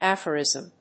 音節aph・o・rism 発音記号・読み方
/ˈæfərìzm(米国英語), ˈæfɜ:ˌɪzʌm(英国英語)/
aphorism.mp3